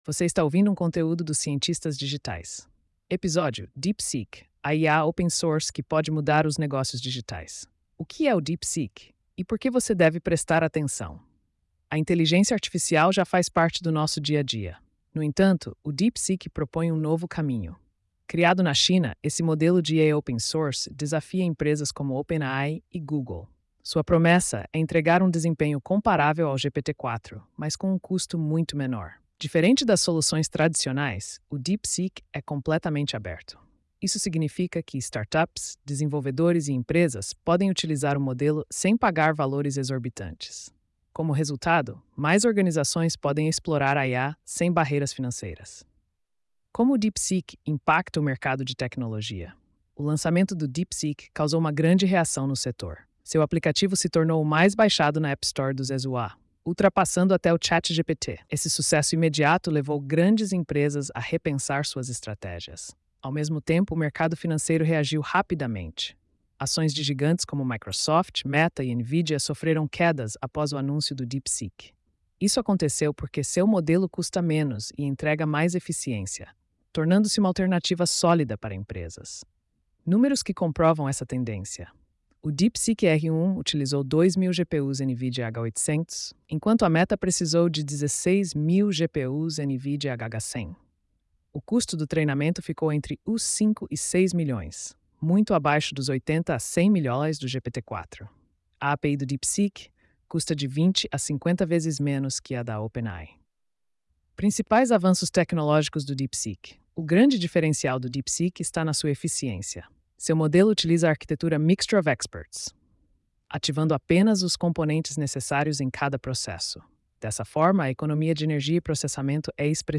post-2800-tts.mp3